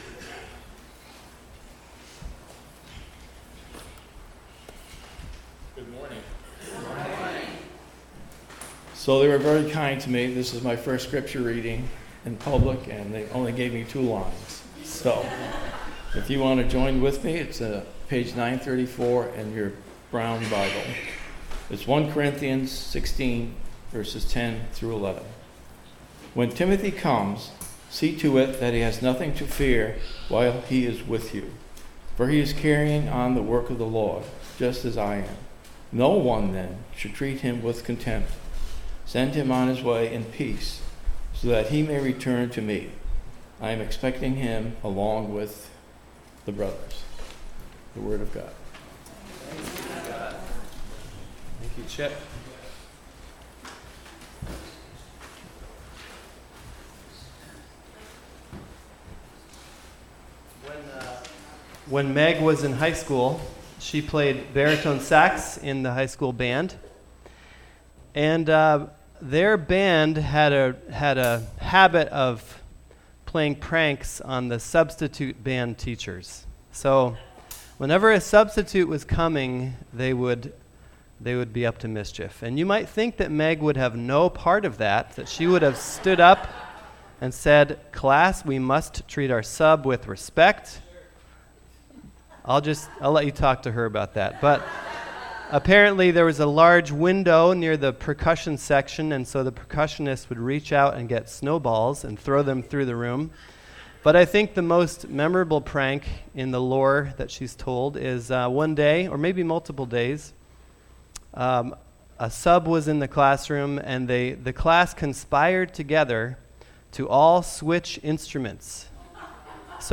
Sunday sermons from Georgia Plain Baptist Church in Georgia, Vermont